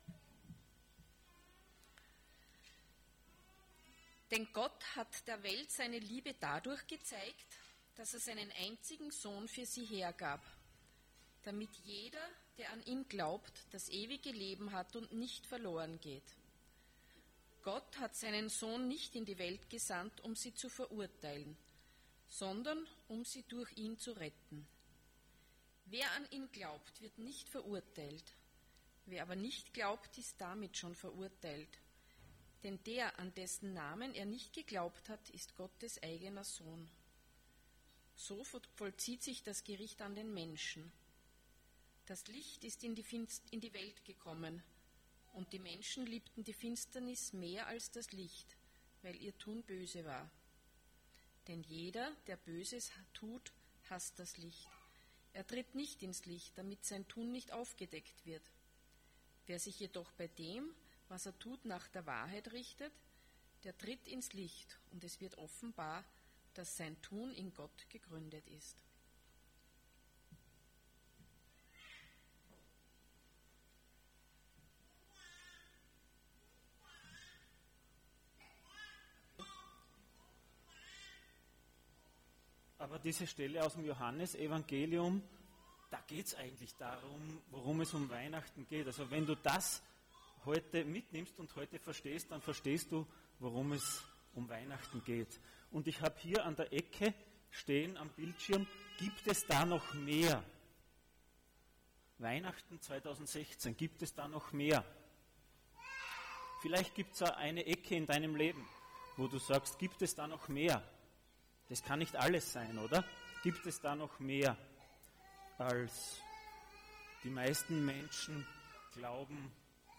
Passage: John 3:16-21 Dienstart: Weihnachten